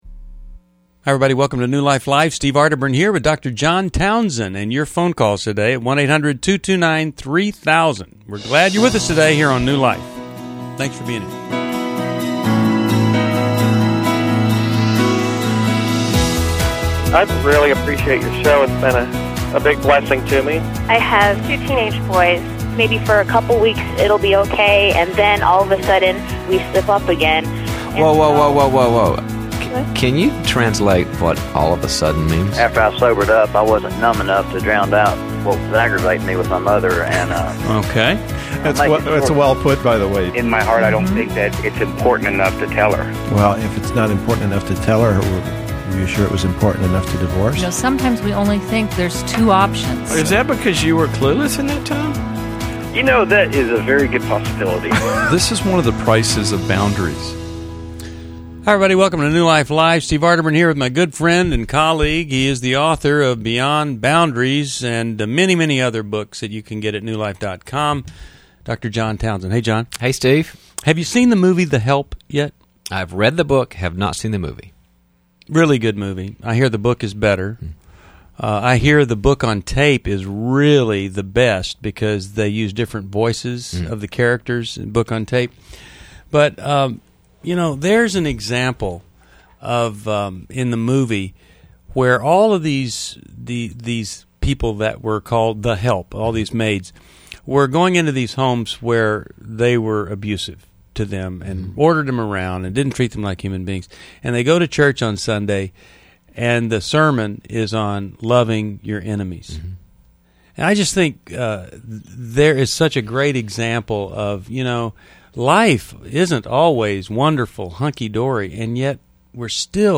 Discover insights on forgiveness, marriage, and family dynamics in New Life Live: October 3, 2011, as hosts tackle tough caller questions and offer guidance.